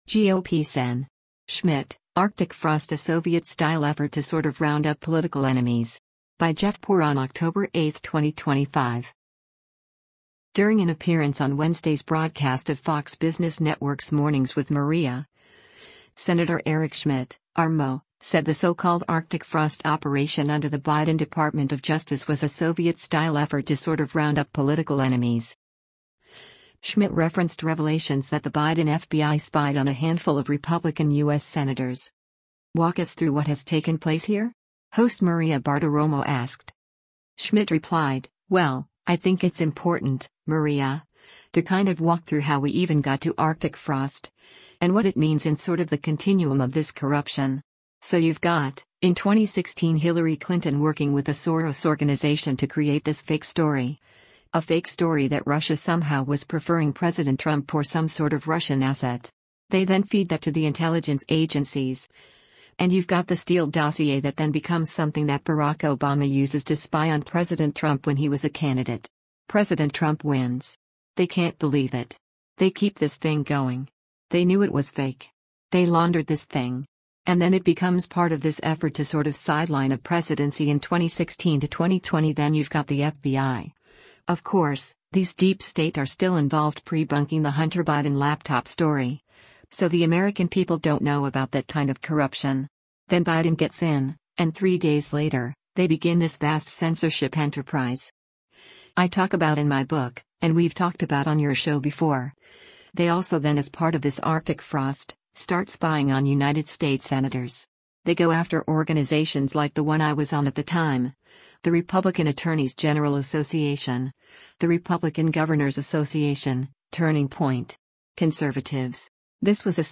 During an appearance on Wednesday’s broadcast of Fox Business Network’s “Mornings with Maria,” Sen. Eric Schmitt (R-MO) said the so-called “Arctic Frost” operation under the Biden Department of Justice was a “Soviet-style effort to sort of round up political enemies.”
“Walk us through what has taken place here?” host Maria Bartiromo asked.